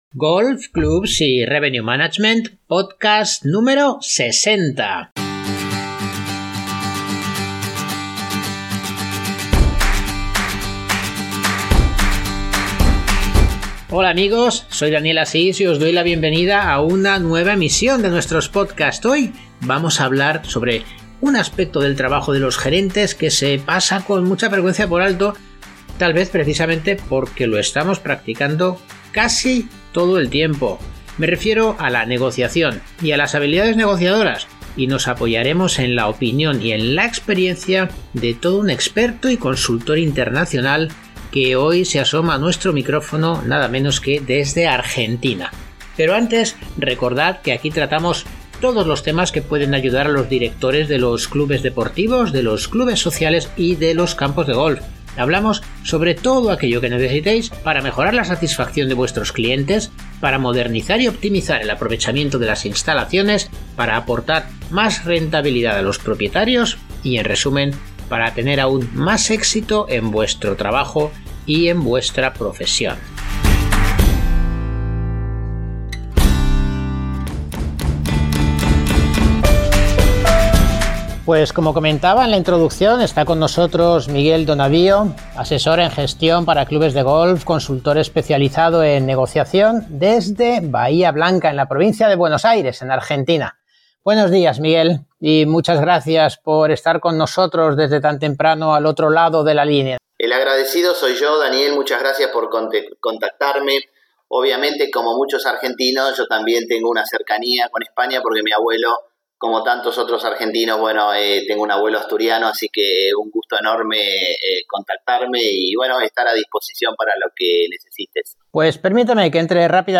Formarse de la forma adecuada, y preparar cada negociación, son algunos de los aspectos que trata durante la entrevista, en la que desgrana el método que ha desarrollado en base a su experiencia.